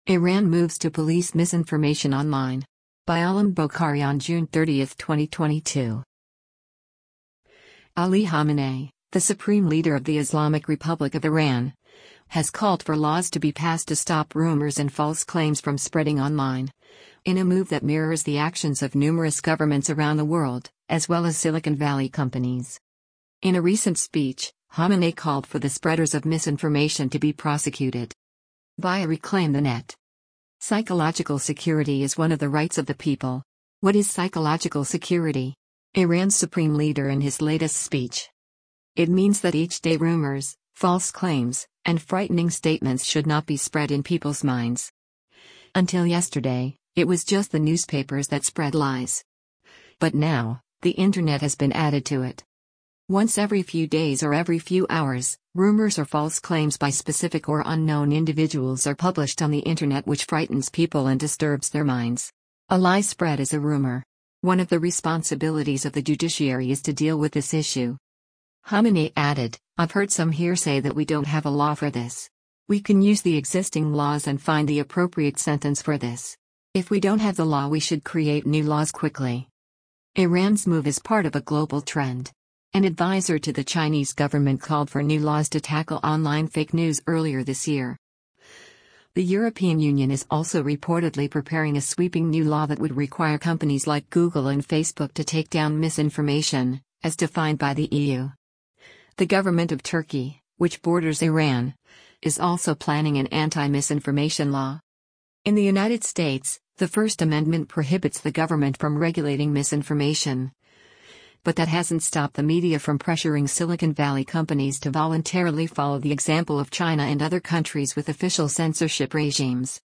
In a recent speech, Khamenei called for the spreaders of misinformation to be prosecuted.